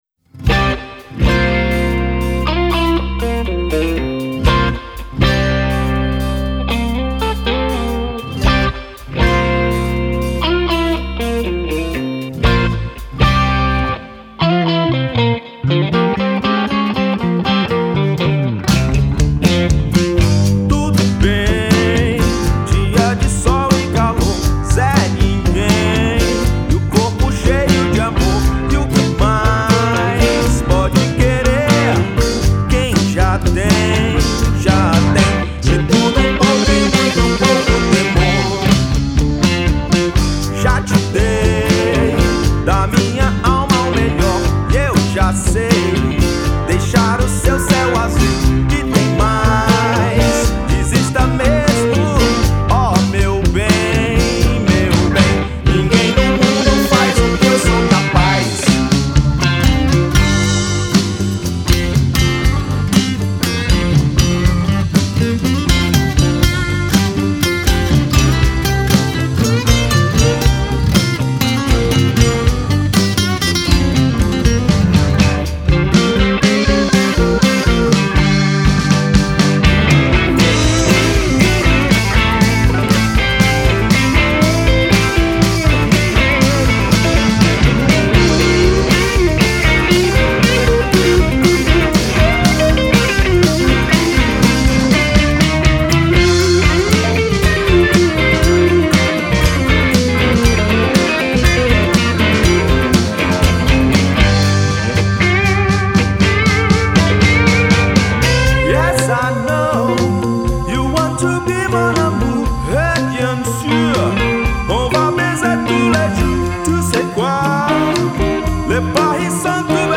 2265   02:41:00   Faixa:     Jazz